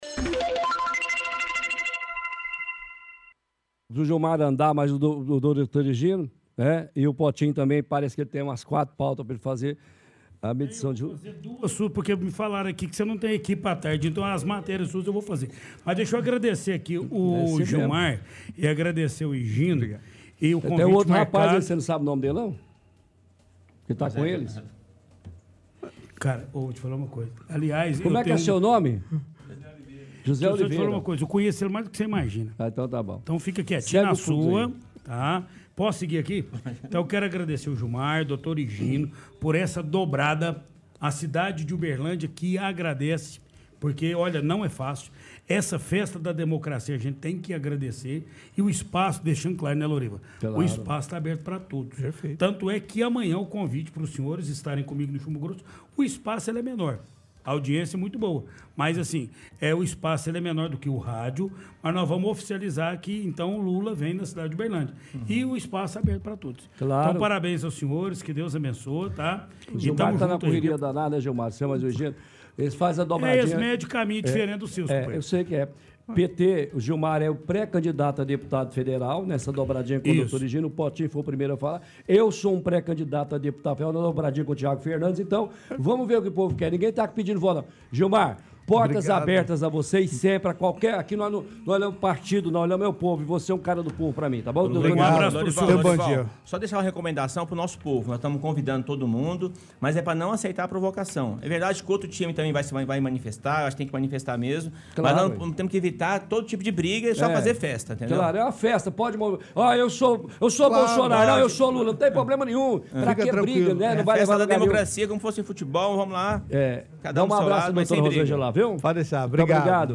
Entrevista com Gilmar Machado